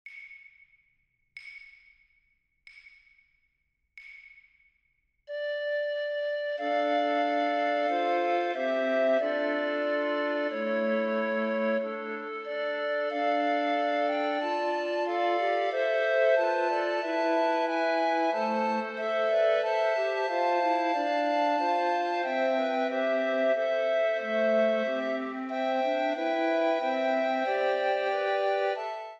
A T T B
for ATTB recorders